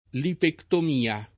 lipectomia [ lipektom & a ]